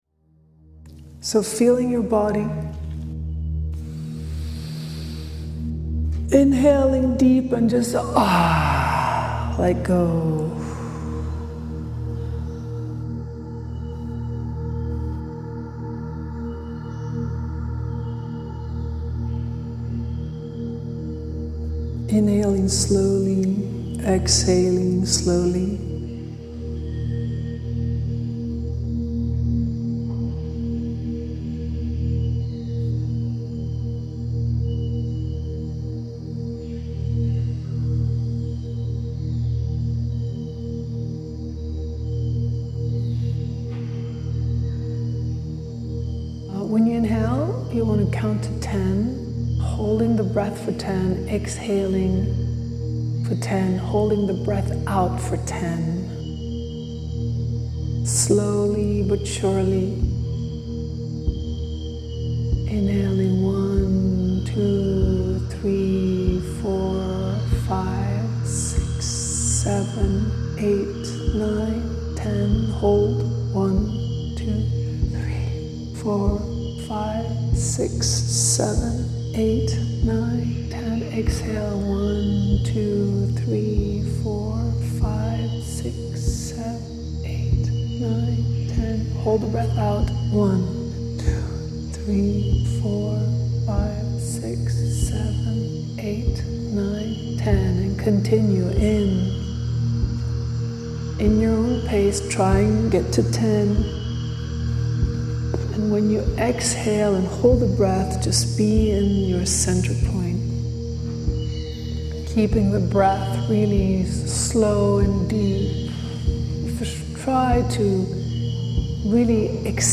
Feel the nurturing energy of Mother Earth envelop you, harmonizing with the vibrant symphony of Peru’s lush jungles.